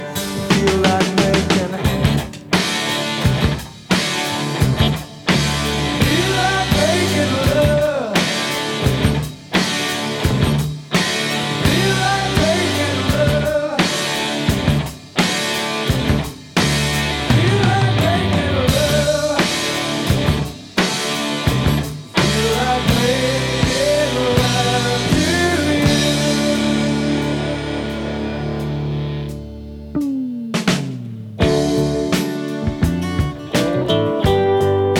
Rock Hard Rock
Жанр: Рок